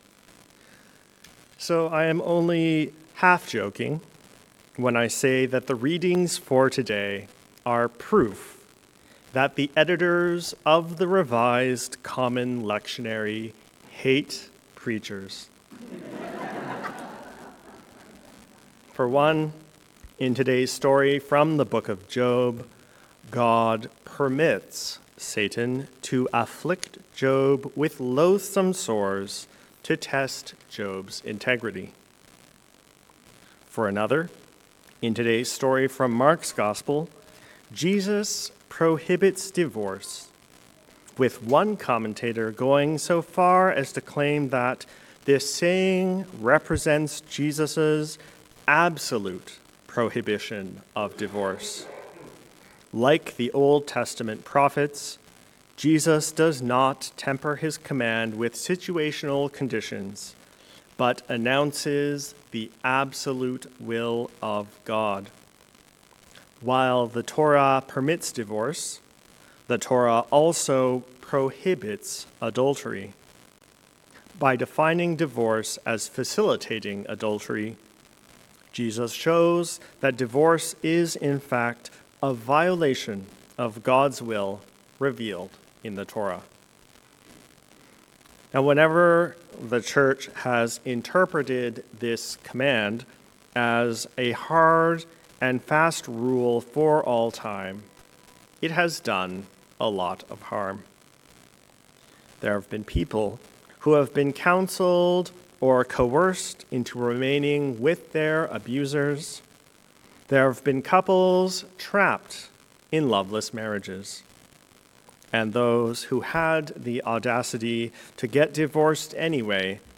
Jesus on Divorce. A Sermon for the Twentieth Sunday after Pentecost